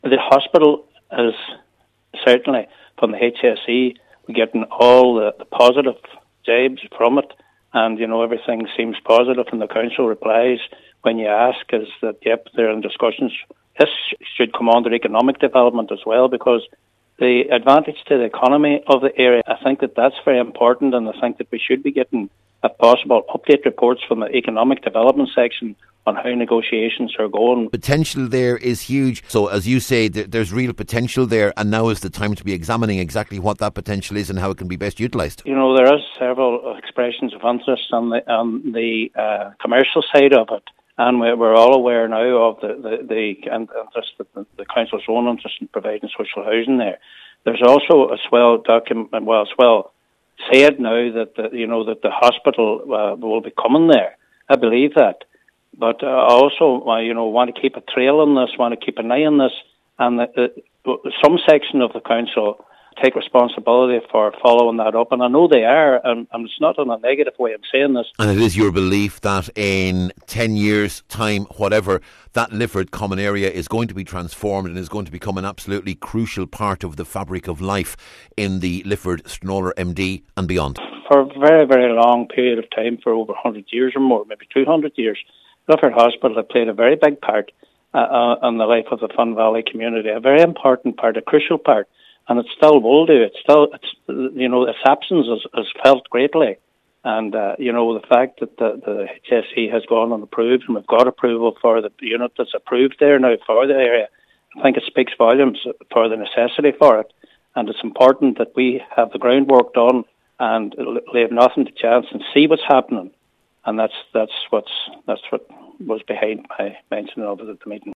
Cllr Gerry Crawford says if the site’s potential is to be maximised, the council’s Economic Development department must become involved………